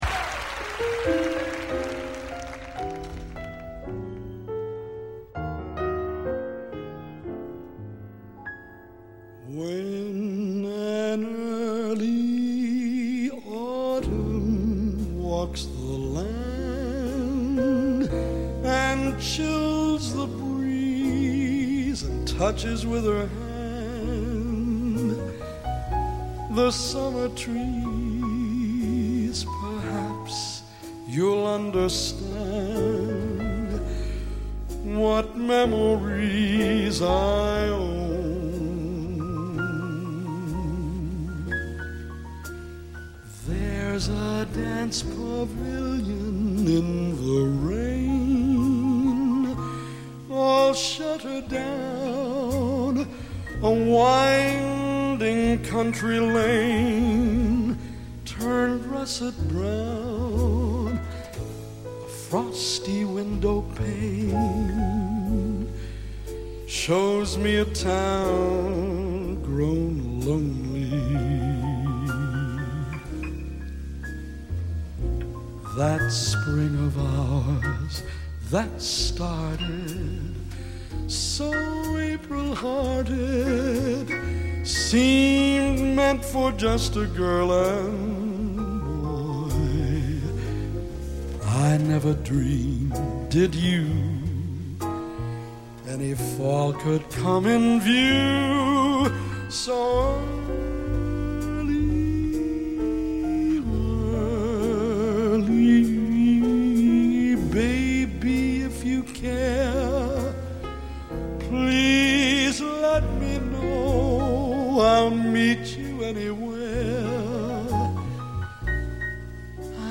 quella melodica, vellutata